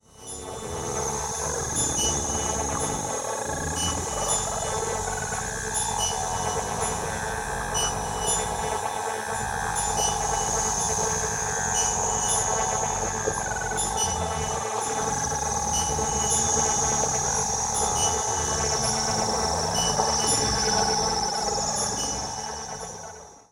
サンプル音源は全てステレオ(2ch)です。